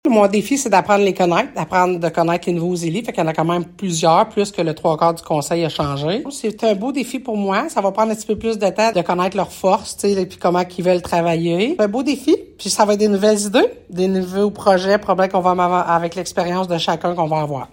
Chantal Lamarche affirme avoir hâte d’apprendre à connaître les nouveaux visages et se dit impatiente de commencer à travailler avec eux :